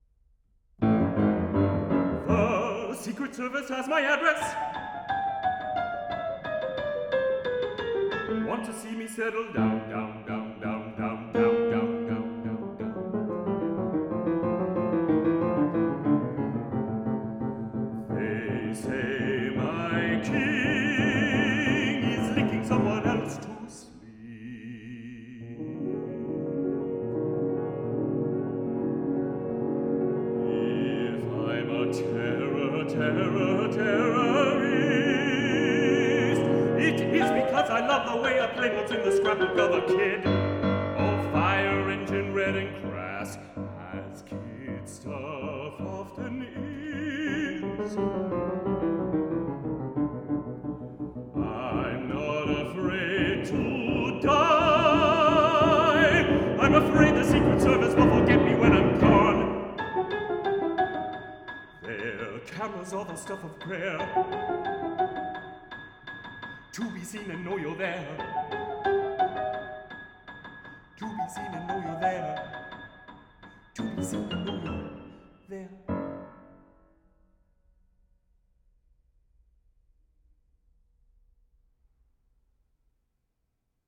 Sought-after baritone